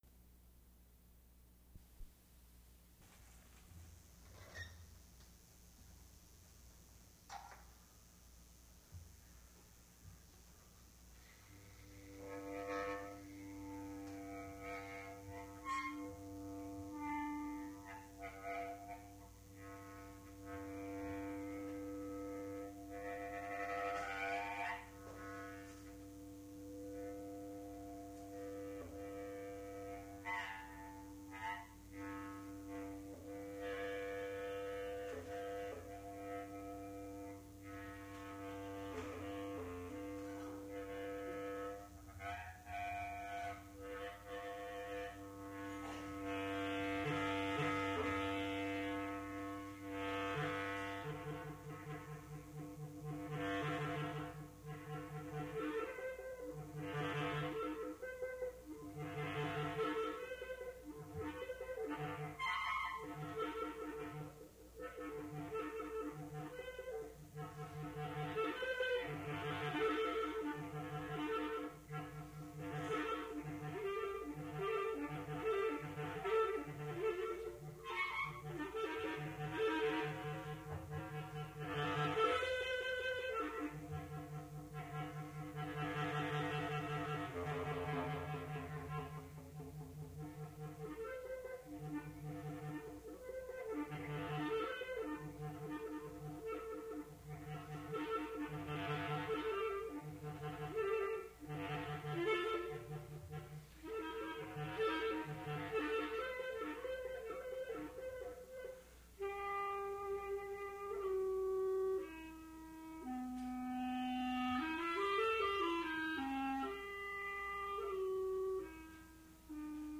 mp3 edited access file was created from unedited access file which was sourced from preservation WAV file that was generated from original audio cassette. Language English Identifier CASS.749 Series River Styx at Duff's River Styx Archive (MSS127), 1973-2001 Note no introduction recorded. Recording begins during a jazz performance